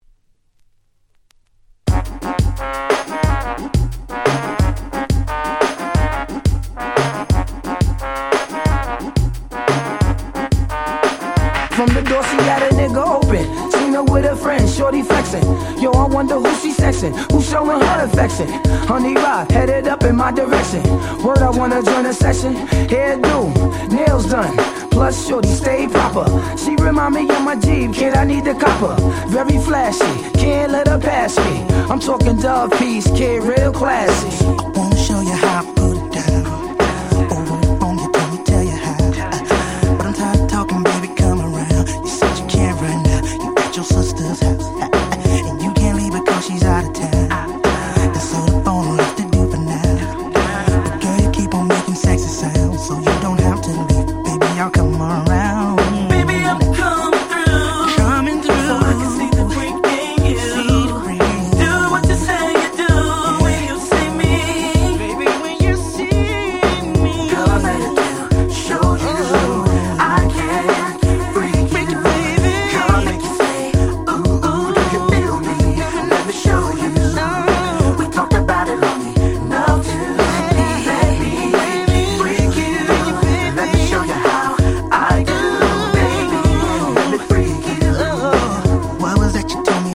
当時HotだったR&B4曲をこの盤オンリーのNice Remixに！！